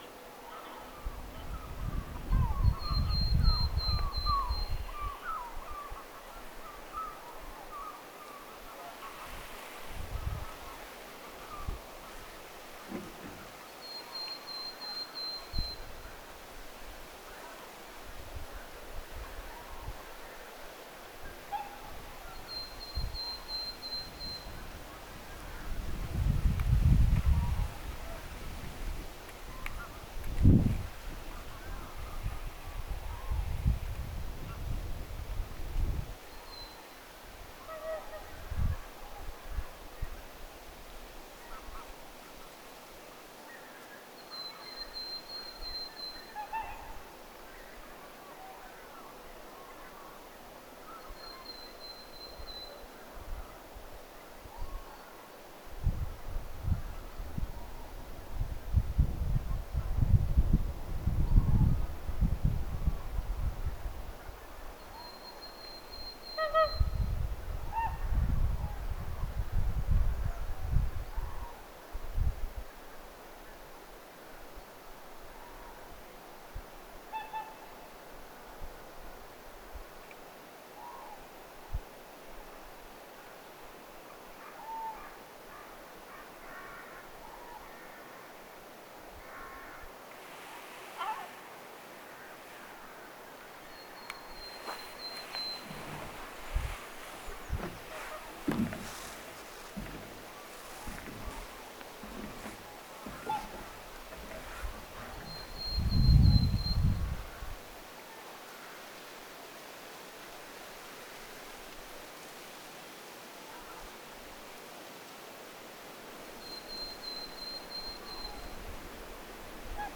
hömötiainen laulaa kevätlaulua
Hömötiaiset pesivät täällä ainakin
homotiainen_laulaa.mp3